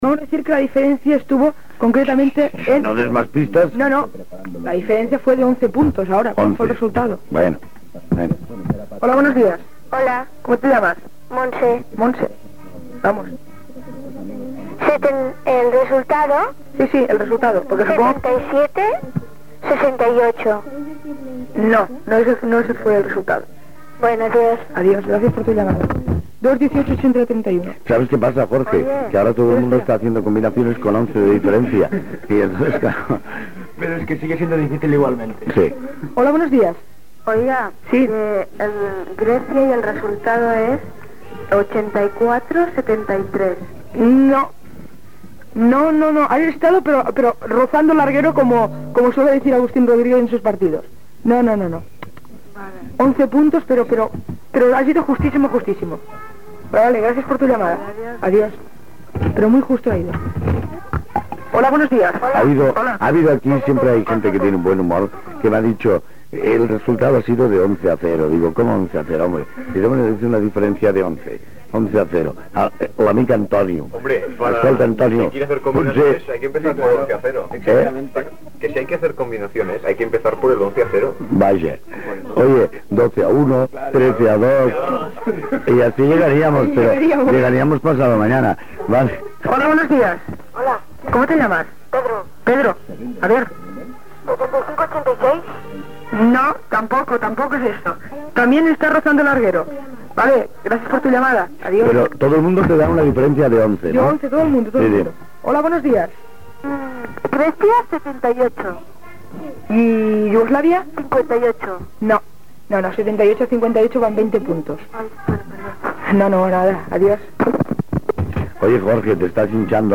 Concurs telefònic, diverses trucades intenten encertar el resultat final d'un partit de bàsquet.
Infantil-juvenil